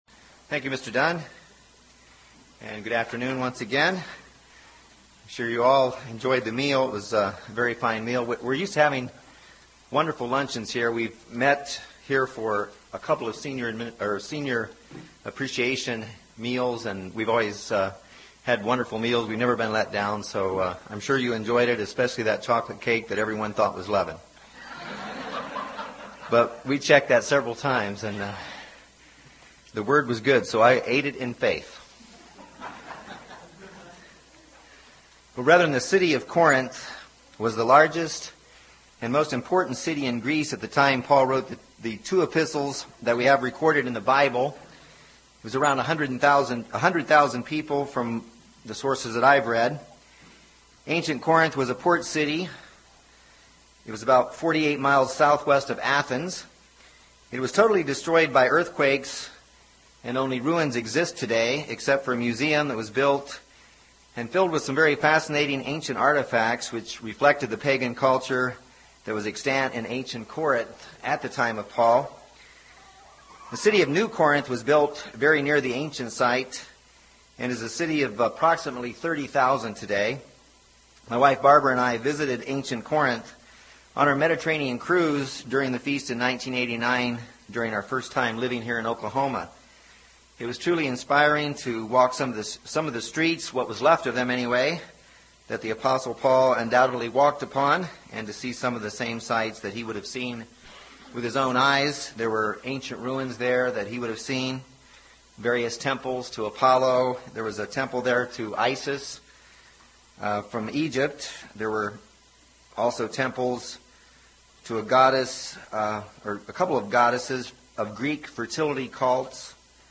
Paul taught many valuable lessons to the members of the Church at Corinth. This sermon considers seven vital lessons from Corinth that still apply to God’s people today as we all strive to become spiritually unleavened.